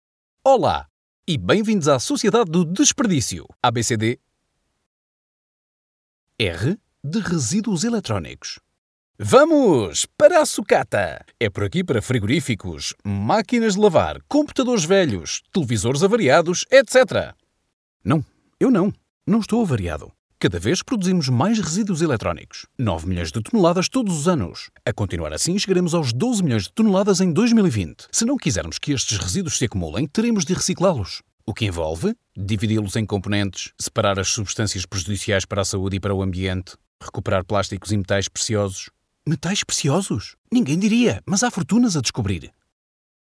Natuurlijk, Speels, Vriendelijk, Warm, Zakelijk